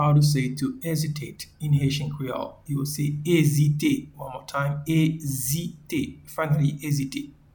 Pronunciation and Transcript:
to-Hesitate-in-Haitian-Creole-Ezite-2.mp3